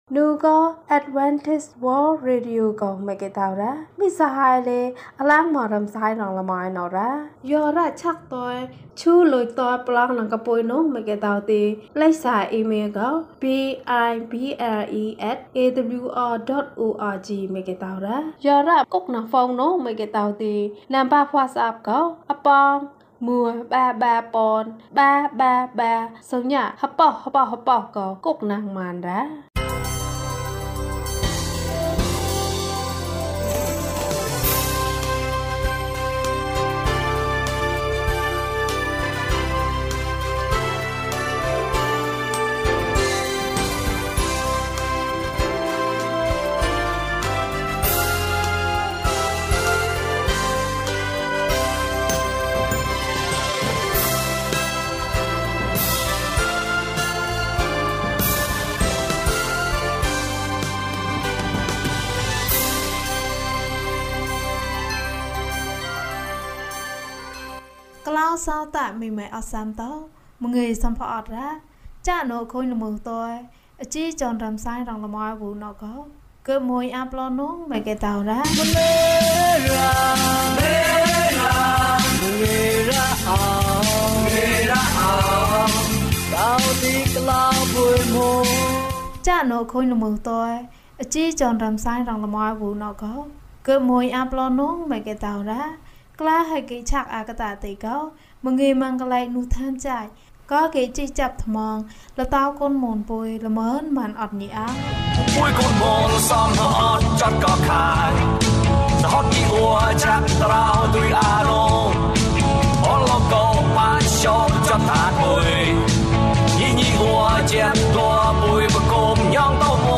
ယောဟန်၏ဘဝ။ ကျန်းမာခြင်းအကြောင်းအရာ။ ဓမ္မသီချင်း။ တရားဒေသနာ။